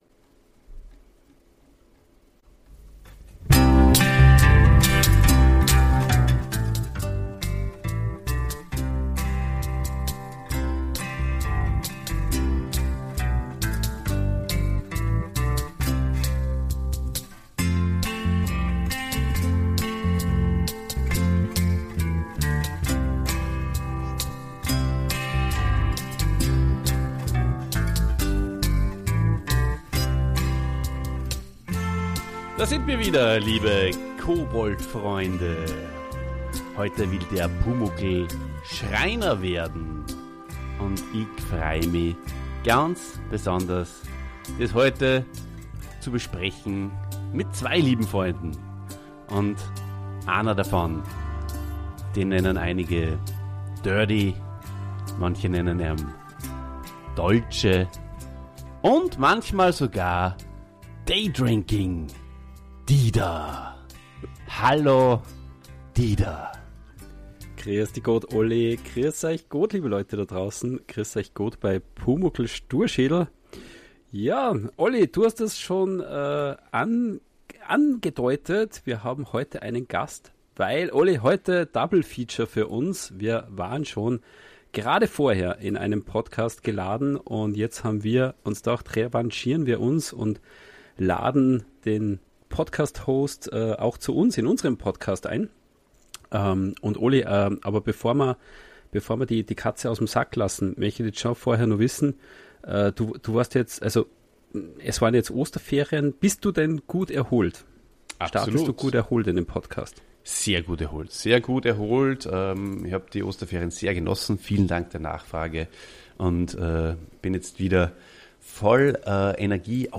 Freut euch auf eine euphorische Besprechung mit einem schwäbisch-fluchendem Abschluss!